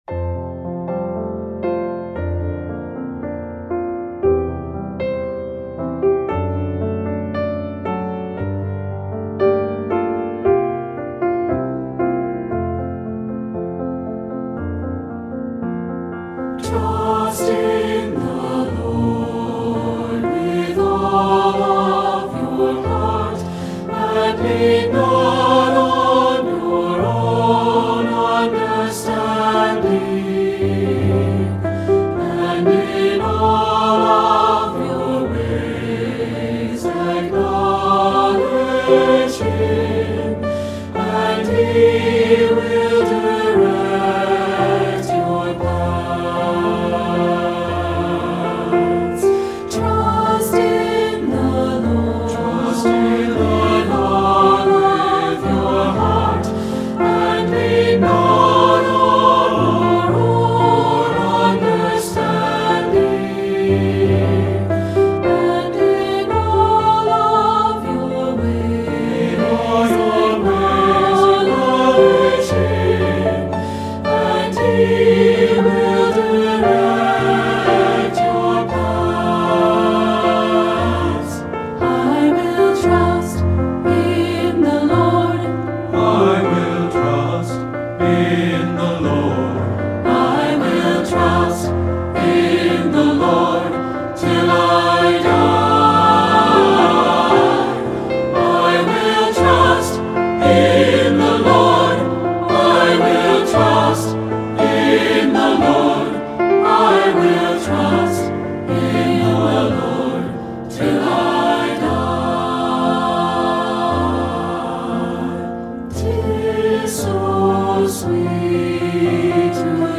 Voicing: SA(T)B and Piano